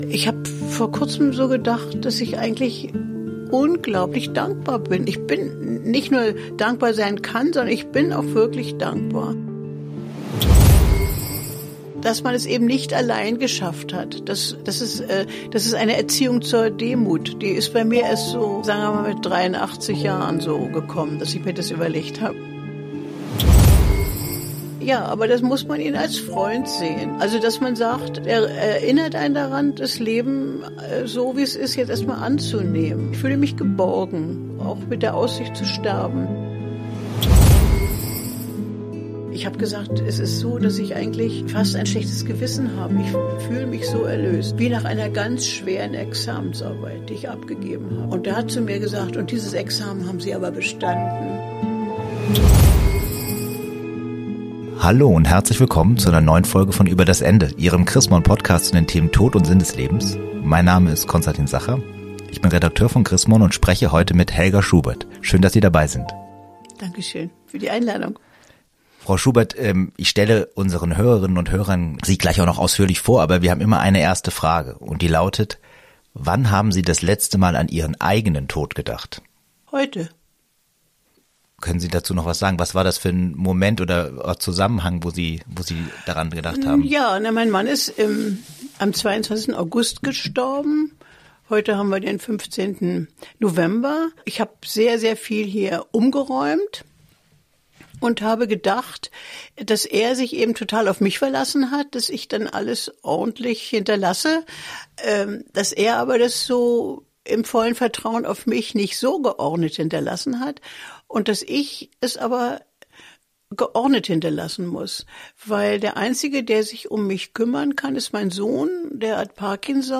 zu Hause besucht und mit ihr über den Tod ihres Mannes und das neue Leben gesprochen